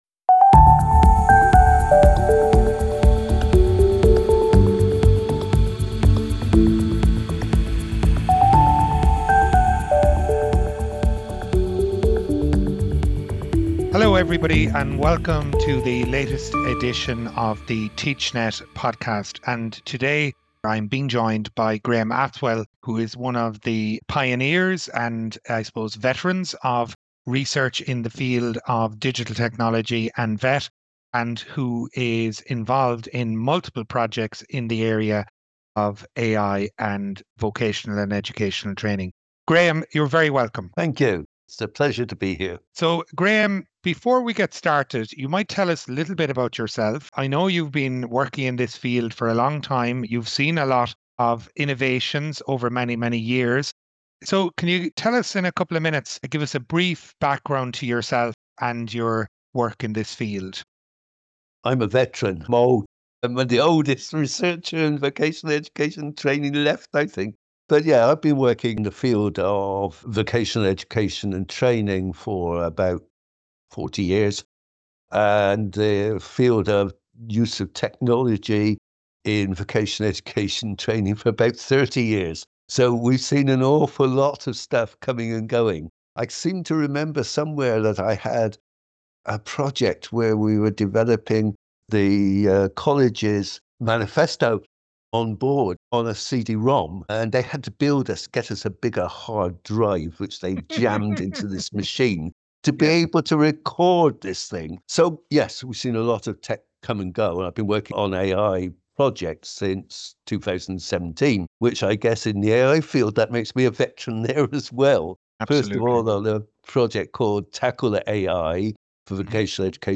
The conversation